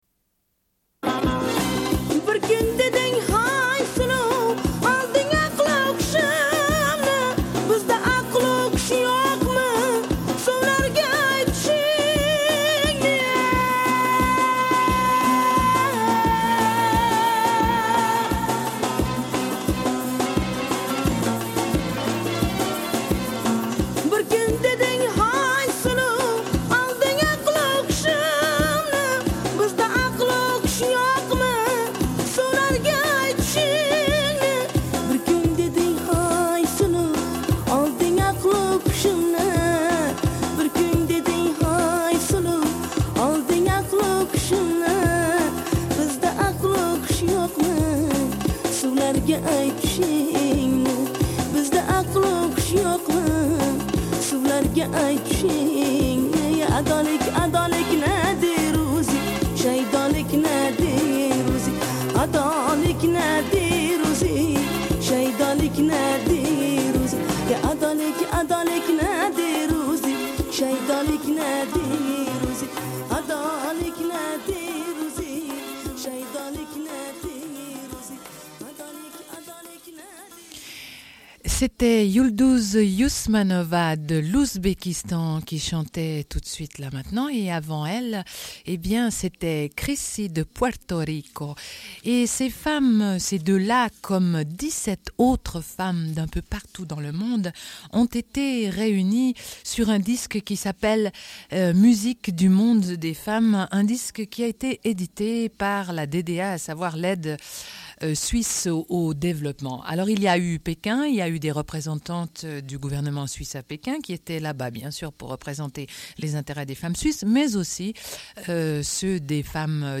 Deuxième partie de l'émission, rediffusion d'un entretien avec les Reines prochaines.